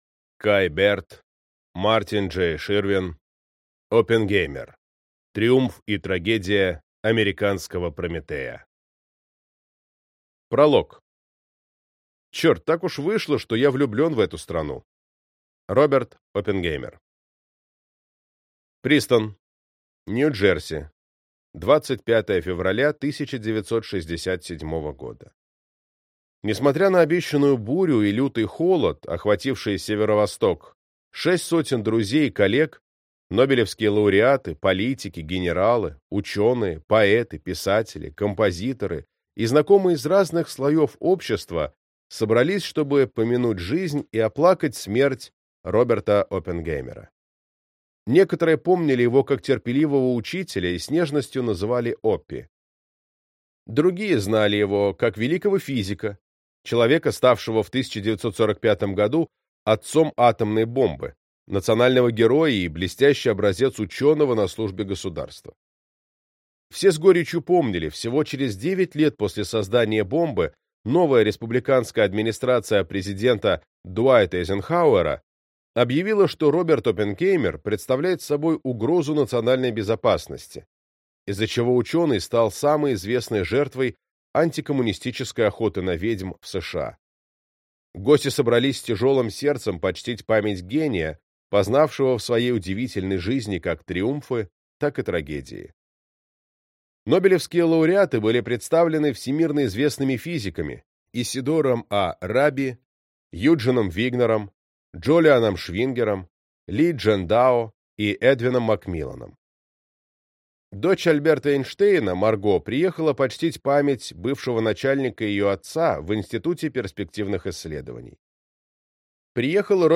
Аудиокнига Оппенгеймер. Триумф и трагедия Американского Прометея | Библиотека аудиокниг